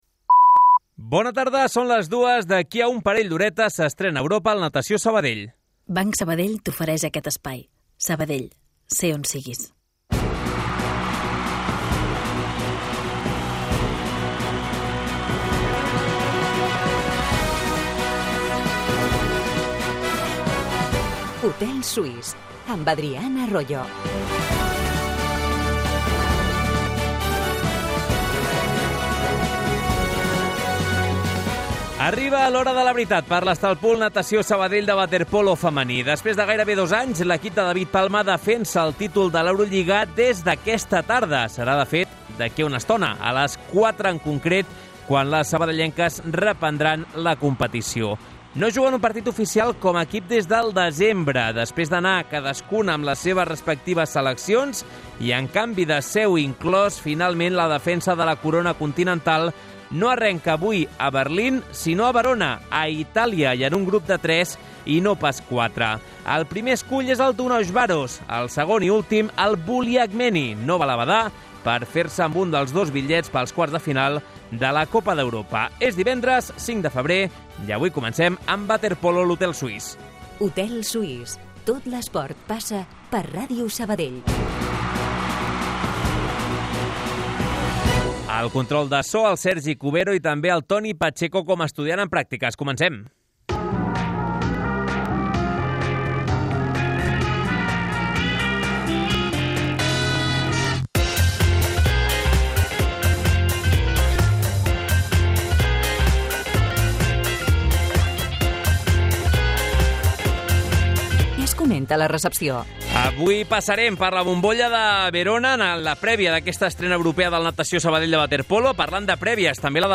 Les tertúlies esportives del recordat Hotel Suís de Sabadell prenen forma de programa de ràdio. Com passava llavors, l'hotel es converteix en l'espai reservat per a la reflexió, el debat i la conversa al voltant de l'esport de la ciutat.